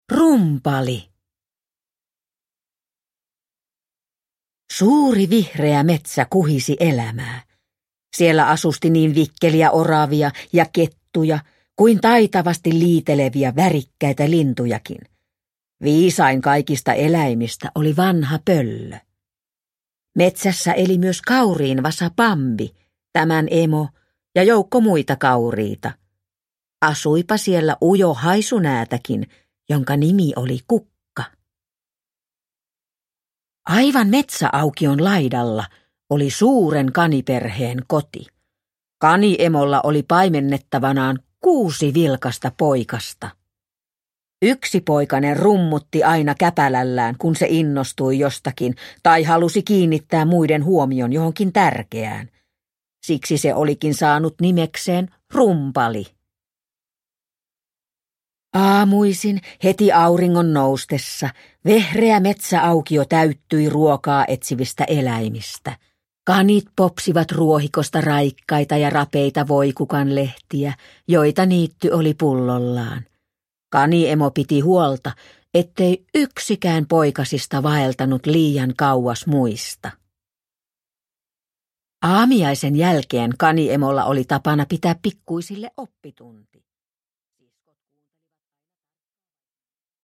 Rumpali – Ljudbok – Laddas ner